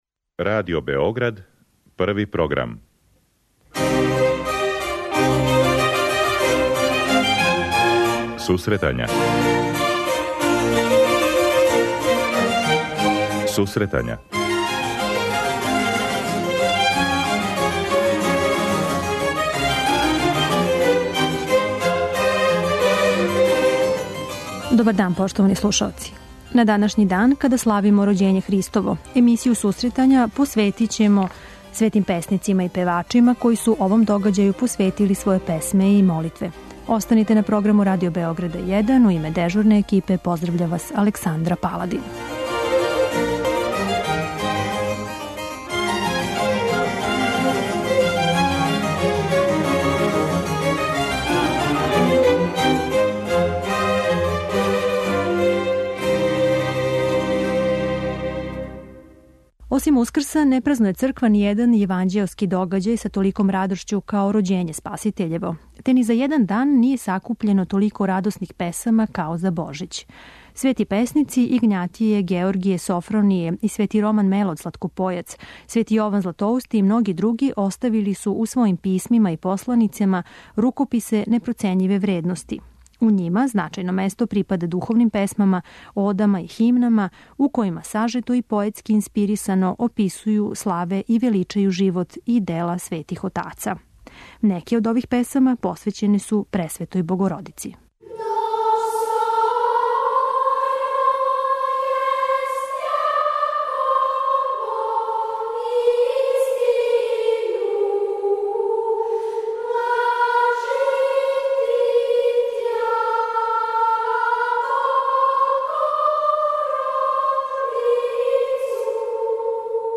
У Божићној емисији говоримо о светим песмама и слушамо одабран репертоар.
Химне, тропари, кондаци и друге врсте духовних композиција.